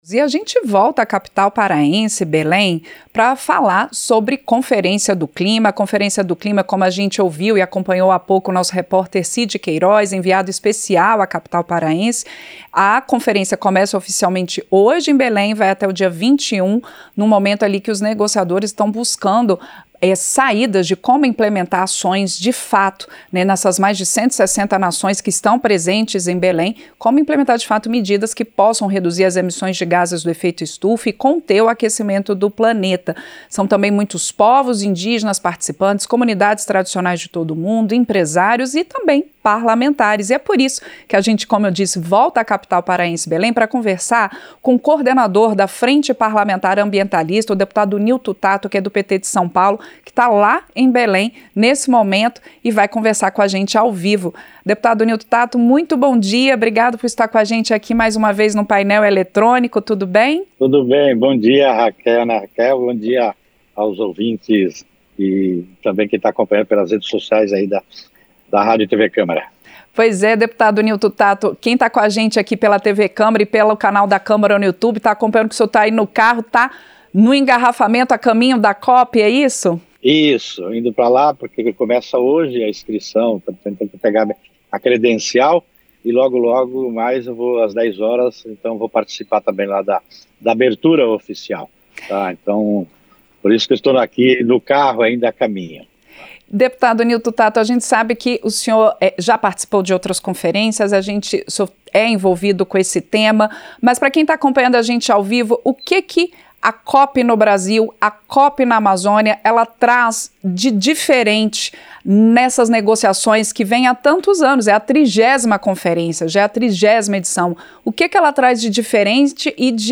Entrevista - Dep. Nilto Tatto (PT-SP)